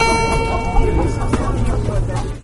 descargar sonido mp3 mercado 3